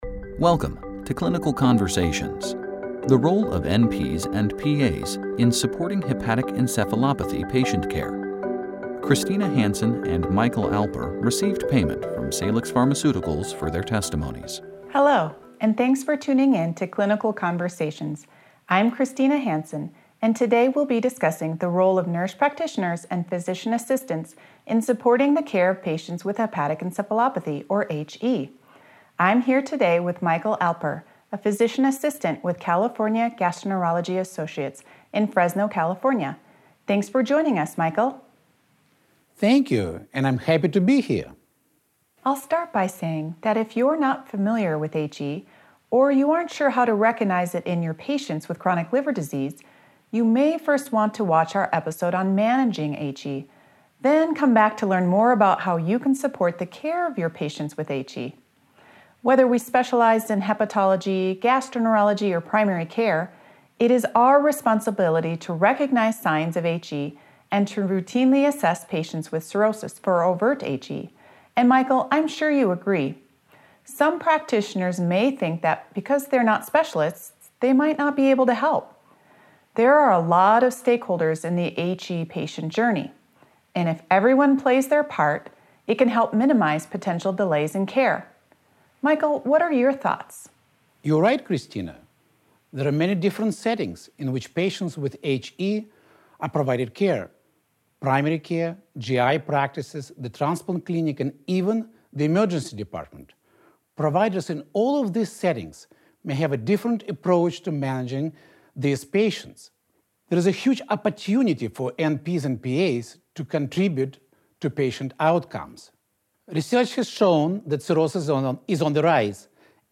Join the clinical conversations as healthcare professionals share clinical insights about diagnosing and managing Overt Hepatic Encephalopathy (OHE) in adults.